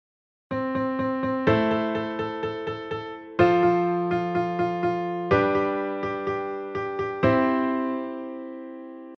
今回は例が6451（キー＝CでAm F G C）なので、各コードのルートでリズムを表したのが↓の音源です。
melodyrythm.mp3